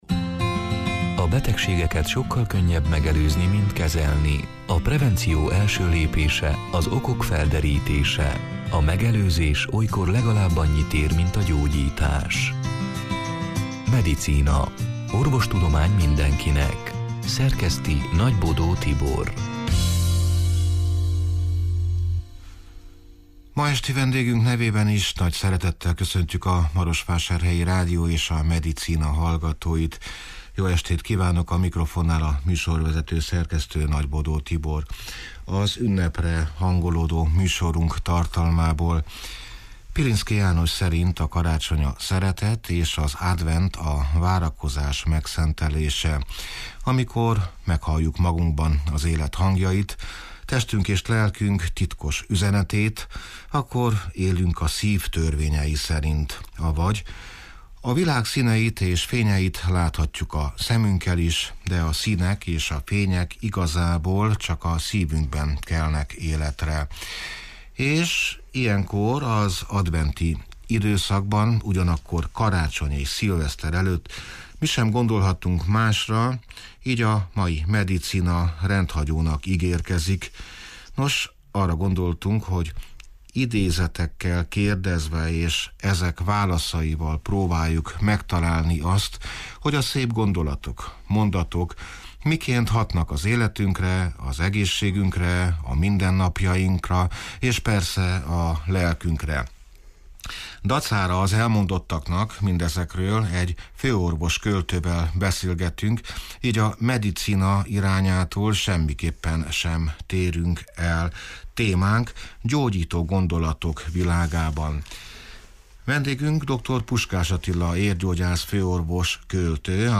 (elhangzott: 2022. december 14-én, este nyolc órától élőben)
Arra gondoltunk, hogy idézetekkel kérdezve, és ezek válaszaival próbáljuk megtalálni azt, hogy a szép gondolatok, mondatok miként hatnak az életünkre, az egészségünkre, a mindennapjainkra és persze a lelkünkre. Dacára az elmondottaknak, mindezekről egy főorvos-költővel beszélgetünk, így a Medicina c. műsorunk formájától semmiképpen sem térünk el…